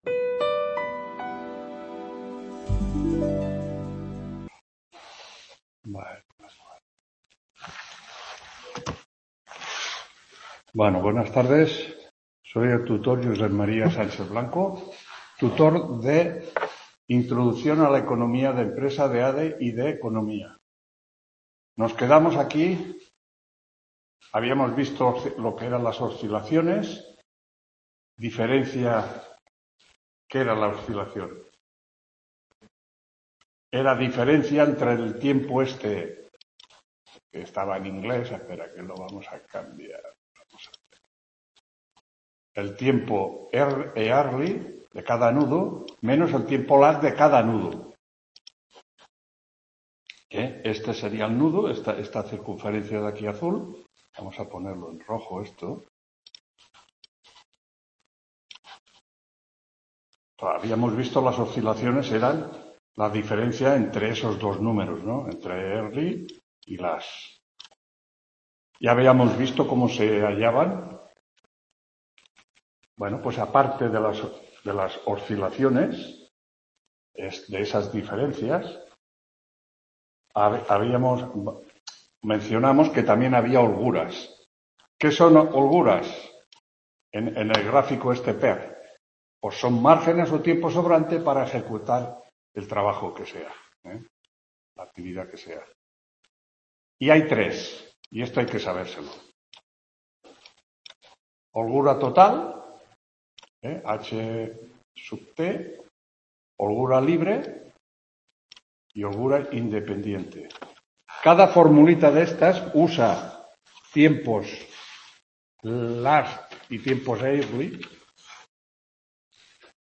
7ª TUTORÍA INTRODUCCIÓN A LA ECONOMÍA DE LA EMPRESA 19… | Repositorio Digital